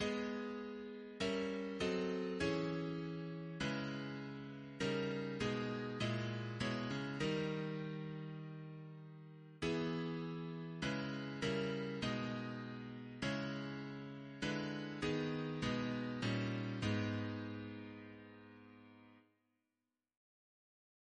Double chant in A♭ minor Composer: Chris Biemesderfer (b.1958)